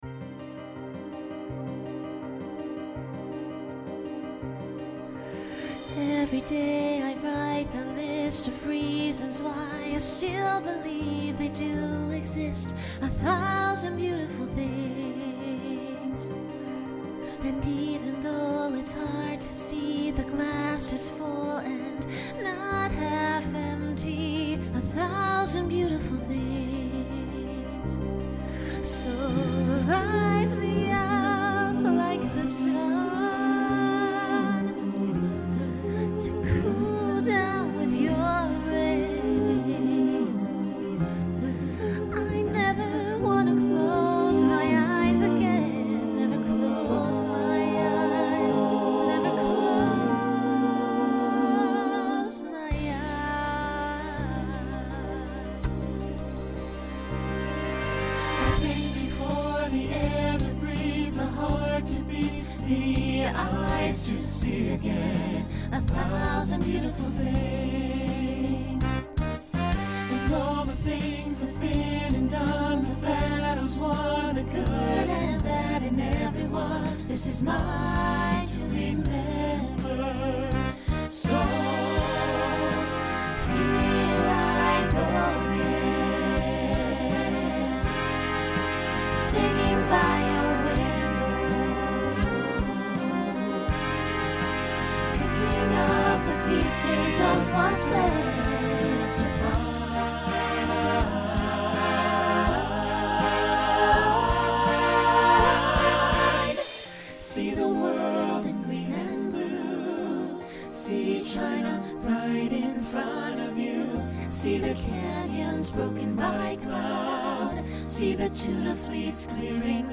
Genre Rock
Function Ballad Voicing SATB